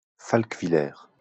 Falkwiller (French pronunciation: [falkvilɛʁ]